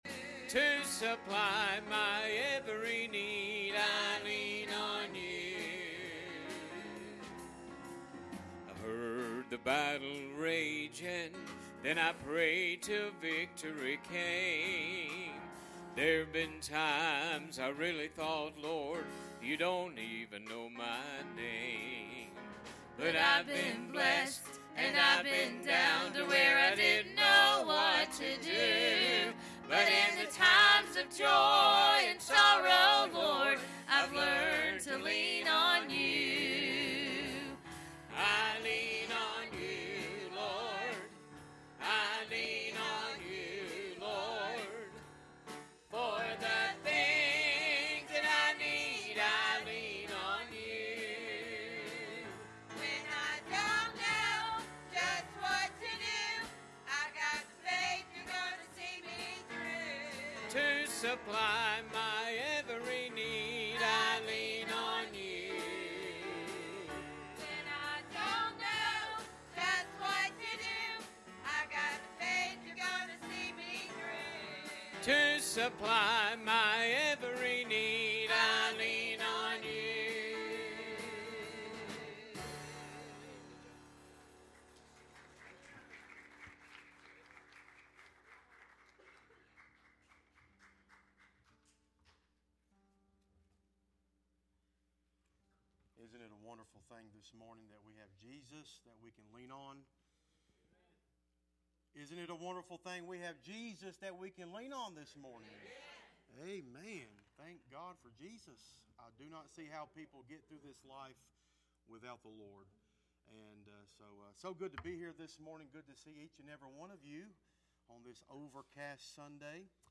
John 15:1-5 Service Type: Sunday Morning « Why ?